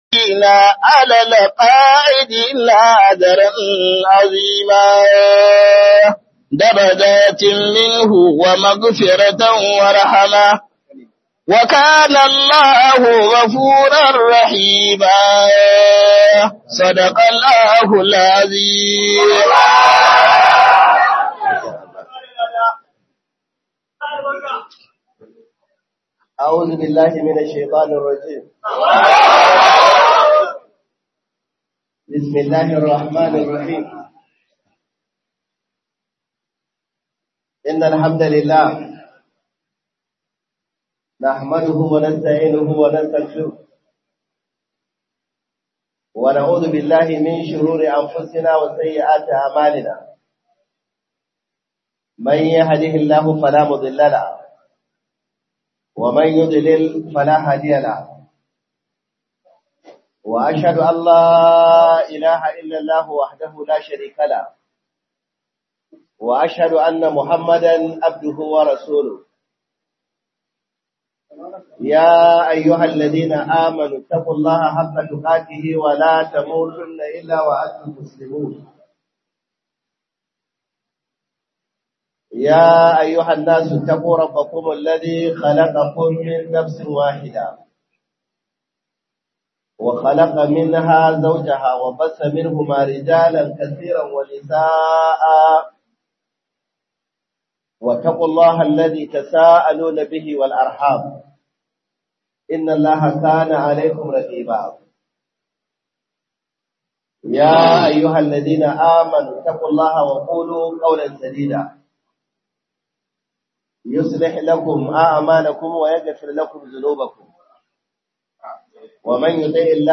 MASU DA'AWA KURINKA YIN HAKURI - MUHADARA by Sheikh (Dr) Kabir Haruna Gombe